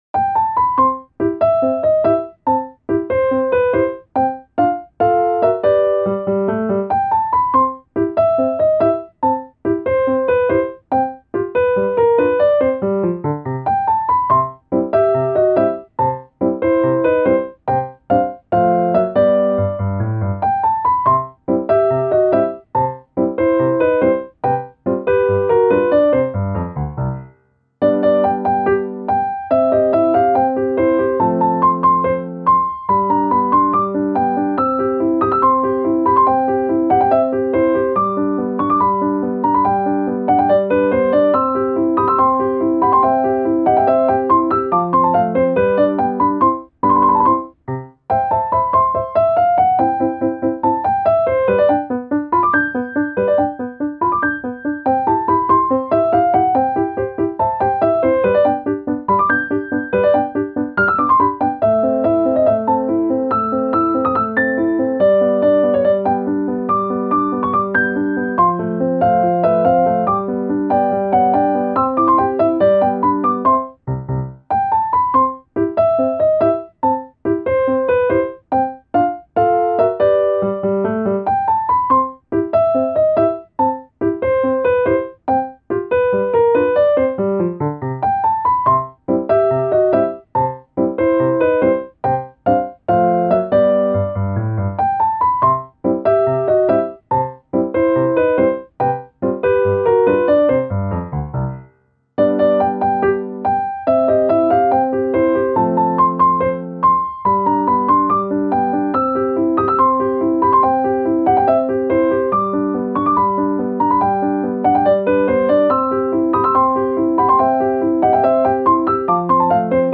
ogg(L) - 楽しい 軽やか かわいい